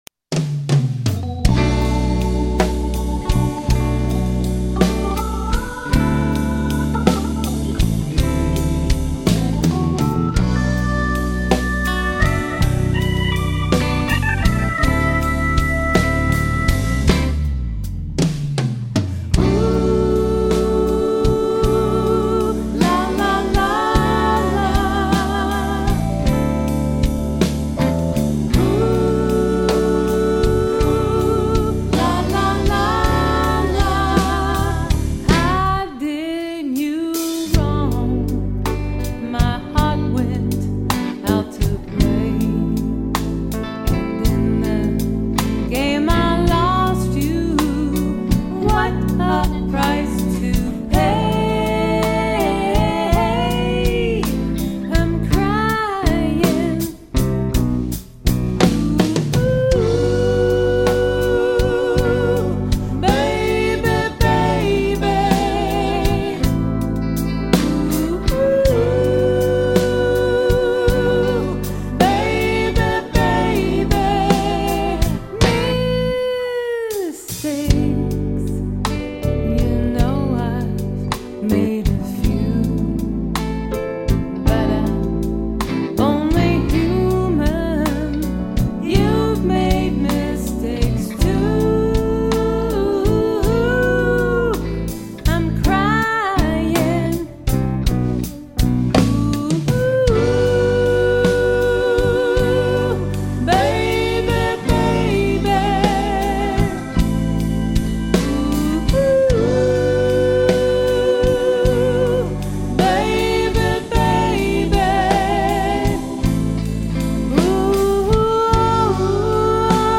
Recorded at Polar Productions Studio, Pismo Beach, CA